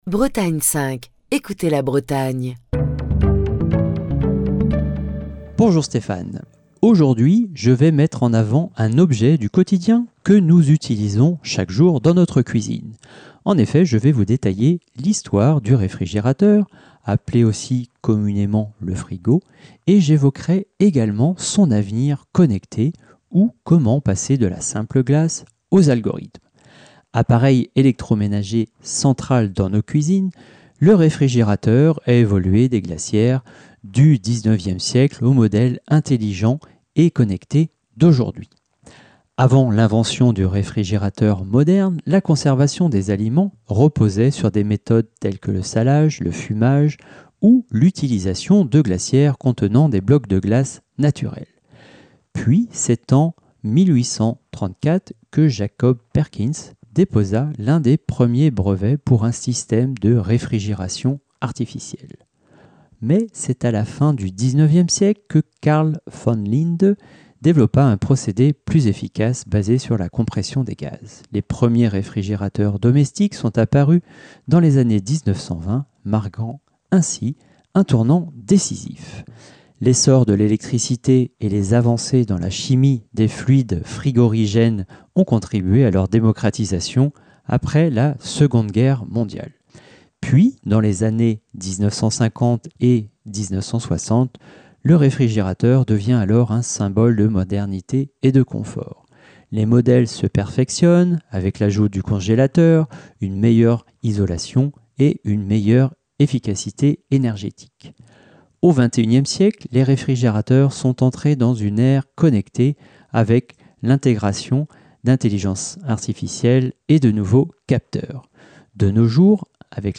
Chronique du 19 mars 2025.